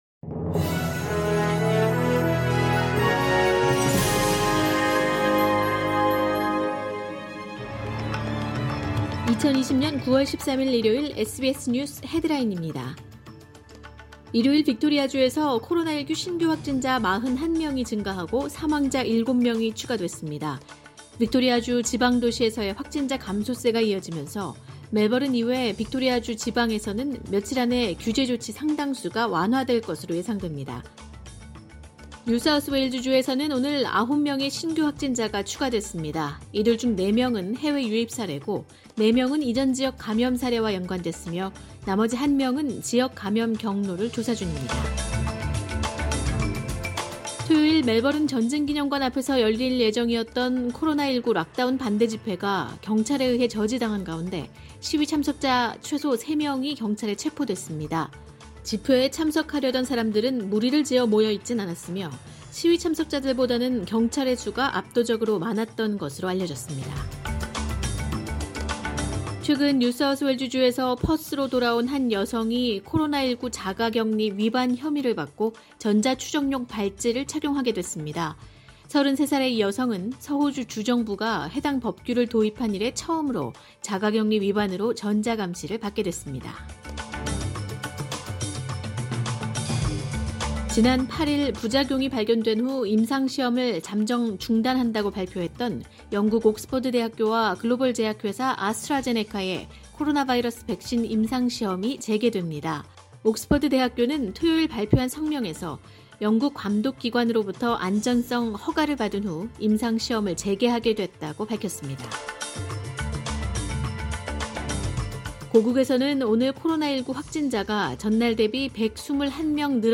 2020년 9월 13일 일요일 SBS 뉴스 헤드라인입니다.